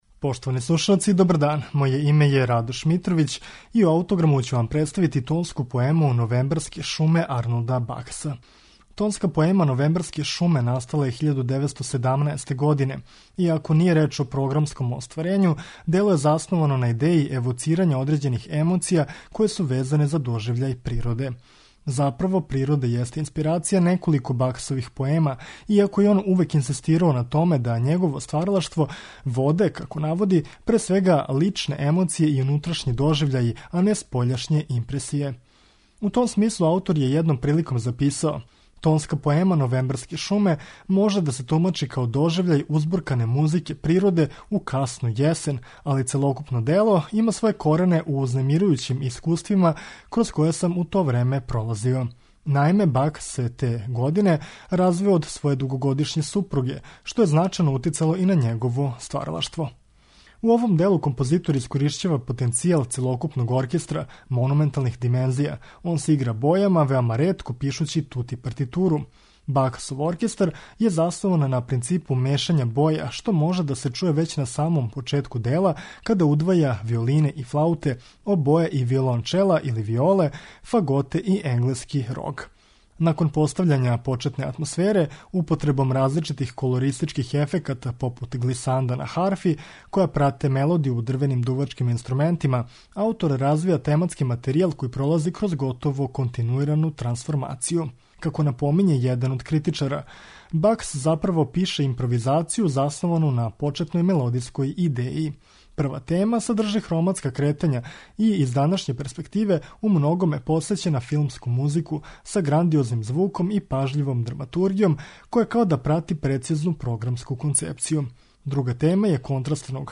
тонска поема